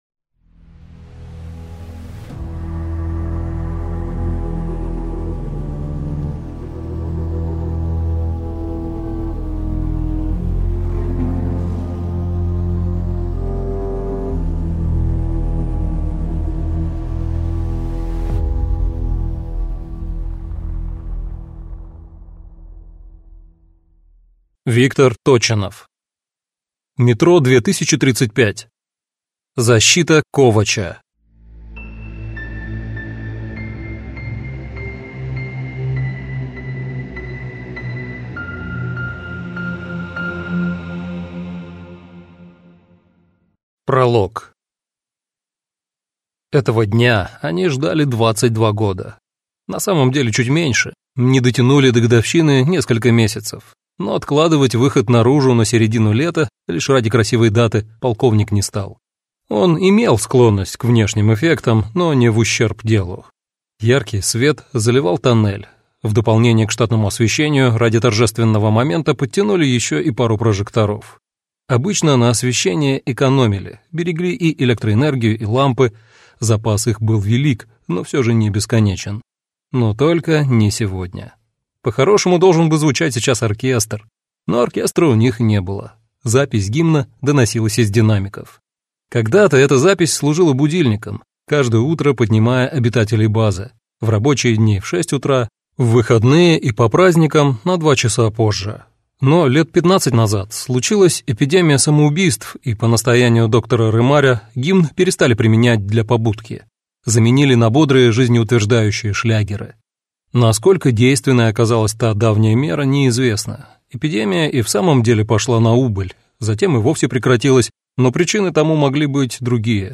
Аудиокнига Метро 2035: Защита Ковача | Библиотека аудиокниг